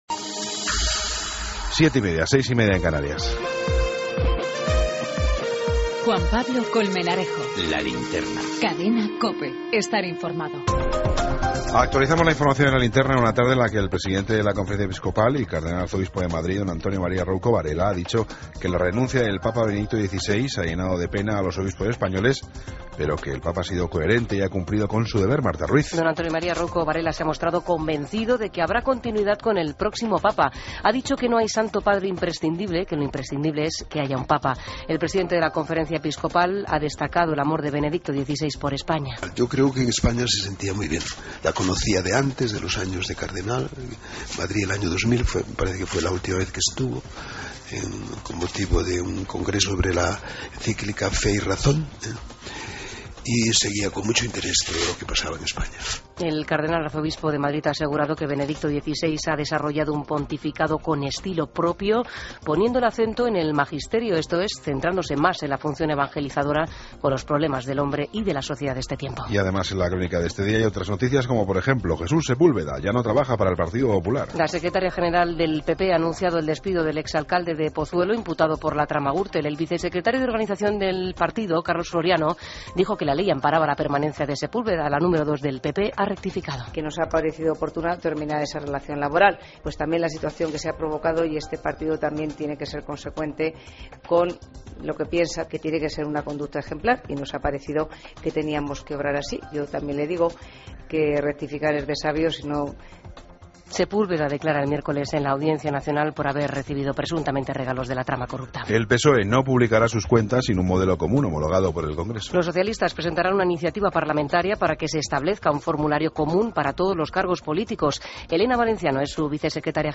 Ronda de corresponsales. Entrevista a Jesús Sanz Pastor, arzobispo...